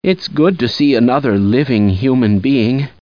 Tags: half life scientist